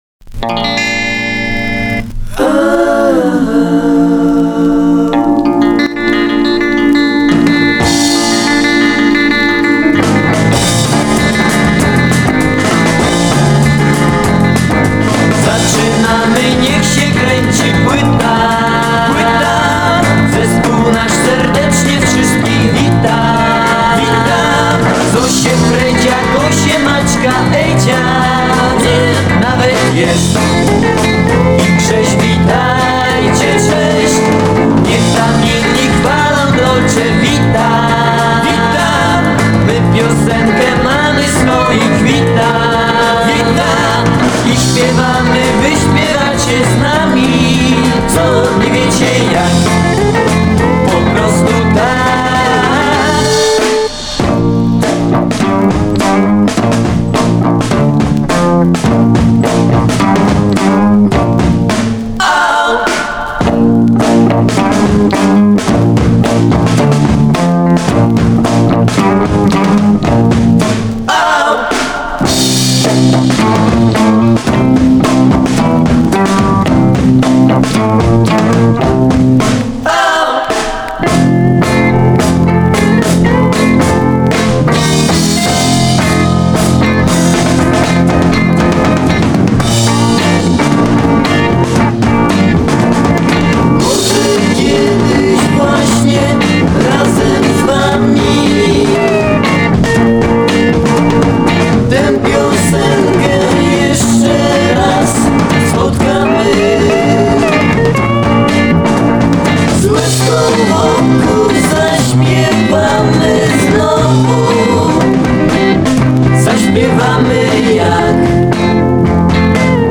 Пионеры польского биг-битa.